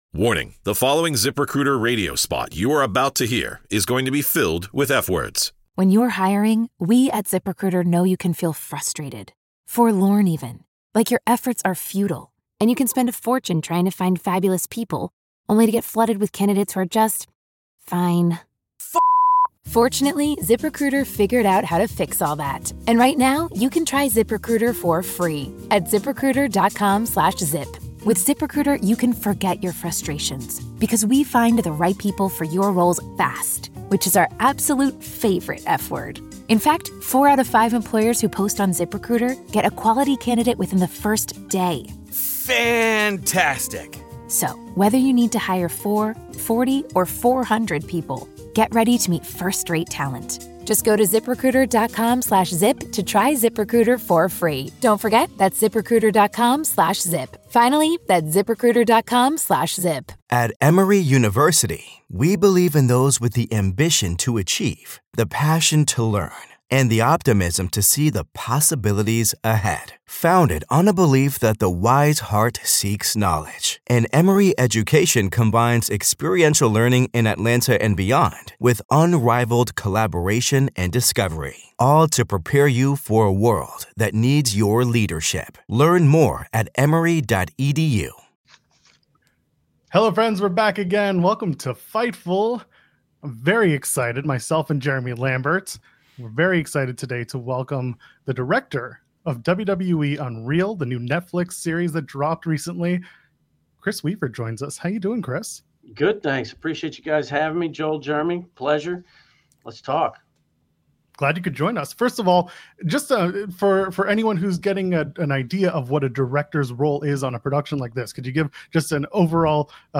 Download - Mariah May Talks STARDOM, Joining Club Venus, Future | 2023 Interview | Podbean